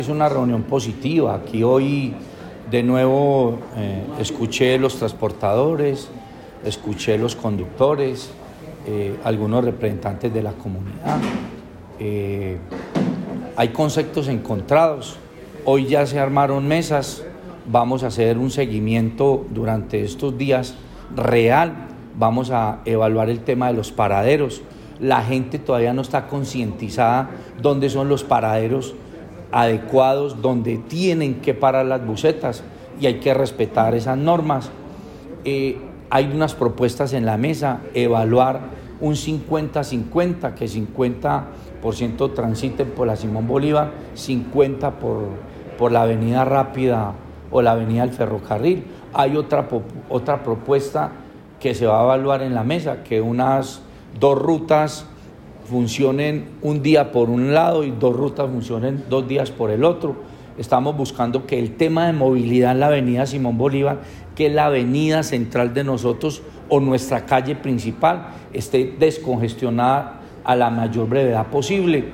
Este encuentro entre el alcalde de Dosquebradas, representantes y propietarios de empresas de transporte y representantes de las personerías y la Defensoría del Pueblo, se cumplió en la sala de juntas de la alcaldía municipal.
Escuchar audio Alcalde Roberto Jiménez Naranjo